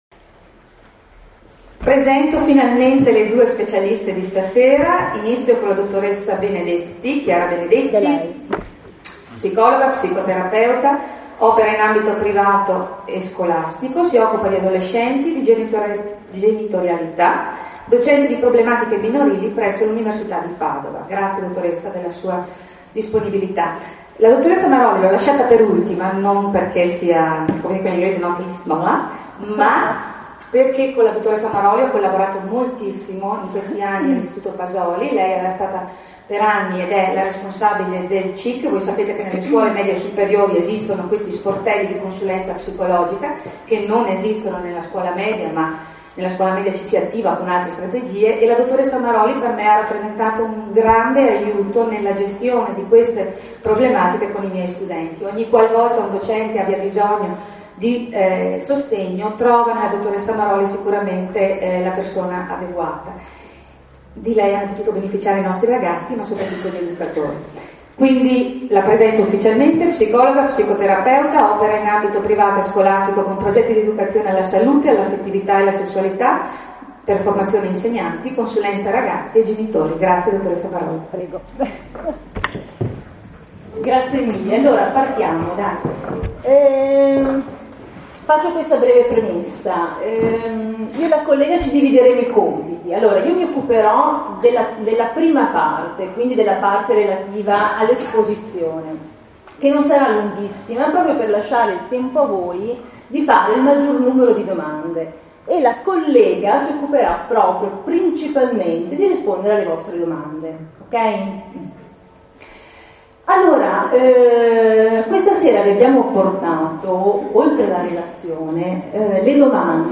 Valanga di temi affrontati al Tommasoli da due professioniste davvero brave in una serata “a due” ben gestita, sfruttando da subito il ritmo del dibattito con le circa 200 persone presenti. Si è parlato di corpo, d linguaggio, di emozioni, di psiche, di relazione, di rispetto, di valori: si è navigato, con un filo conduttore ben preciso, nel mare magnum delle emozioni e dei comportamenti dei nostri ragazzi di fronte al tema della sessualità e dell’affettività.